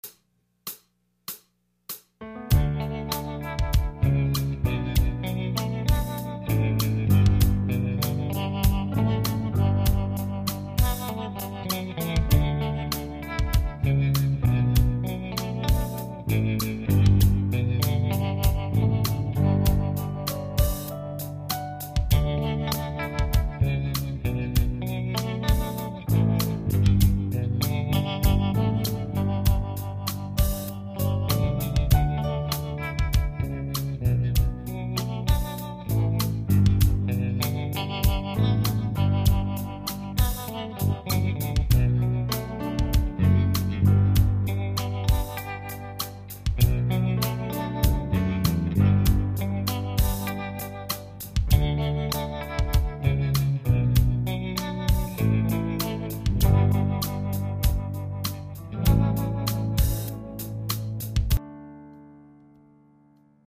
Los Angeles based guitarist